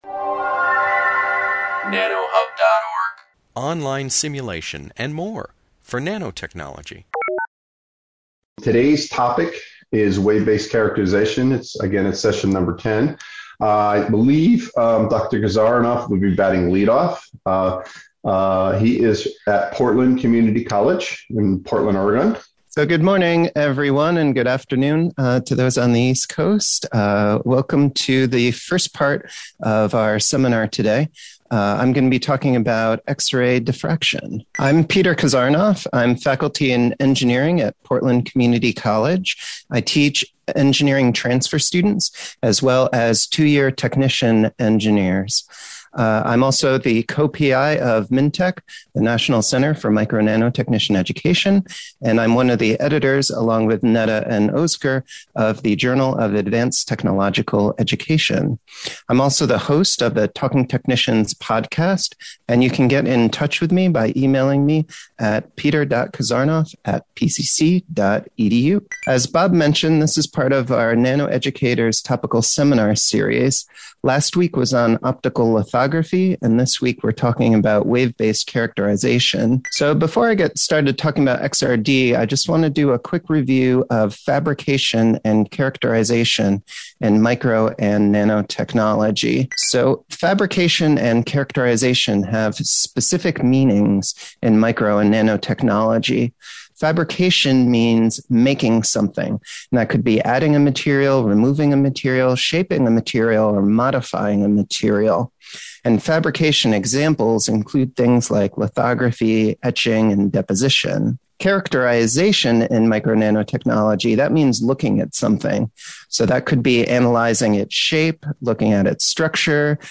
This webinar, published by the Nanotechnology Applications and Career Knowledge Support (NACK) Center at Pennsylvania State University, is the first of a two-part lecture on X-ray diffraction (XRD) for characterization.